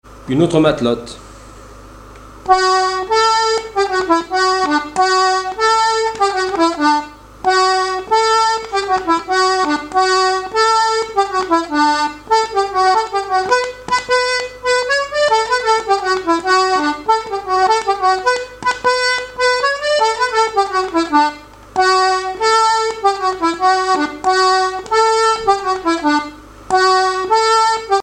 danse : matelote
airs de danse à l'accordéon diatonique
Pièce musicale inédite